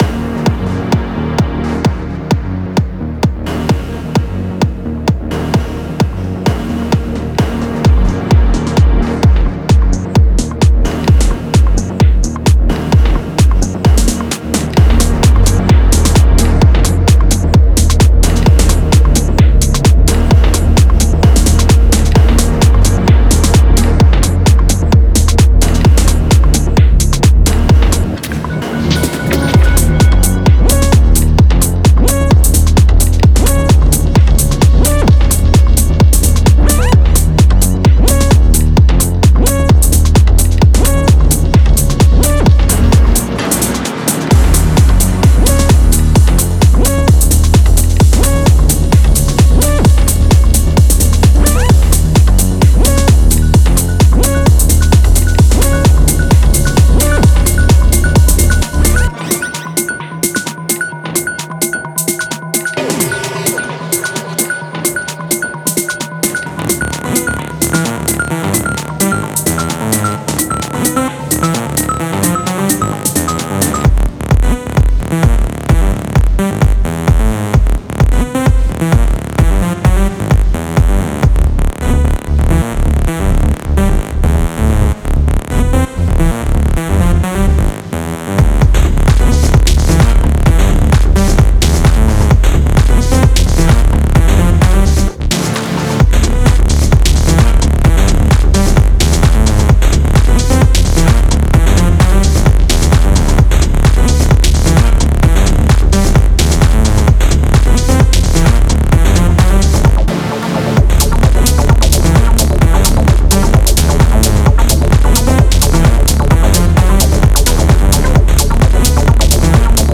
メロディックテクノ系では特に使い勝手も良く、 重宝するサンプルパックです。
Genre:Melodic Techno
深く、催眠的で、感情的、ダークで、そして徹底してアンダーグラウンドです。
あるのは本物の空気感、緊張感、グルーヴ、そして個性だけです。
ベース ダンスフロアを支配するために設計された、深く推進力のある低域です。